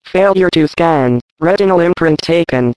scanner
spch_failure1.ogg